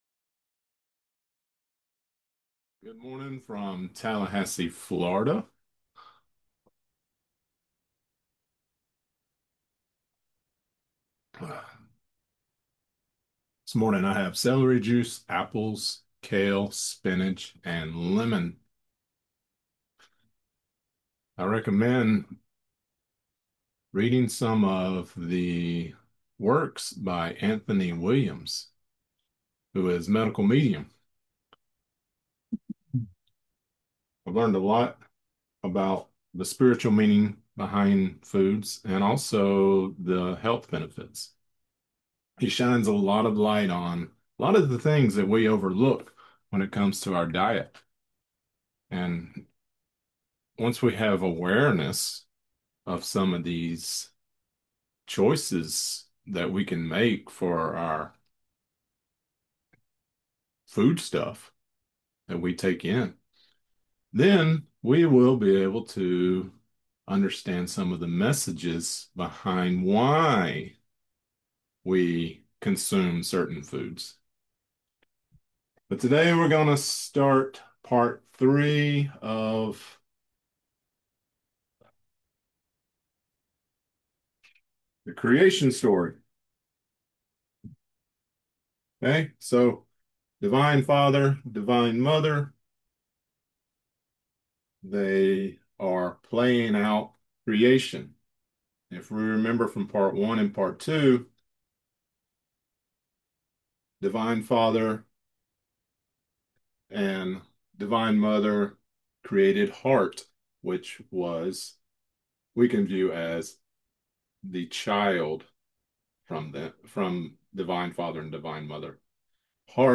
We will walk through the Cosmic Argument and also finish with Key 21, The World, as the Cosmic Argument came to an end with reunited Divine Parents within the Oneness. Lecture Created Transcript Blockchain Transcript 12/04/2025 Audio Only 12/04/2025 Watch lecture: Visit the Cosmic Repository video site .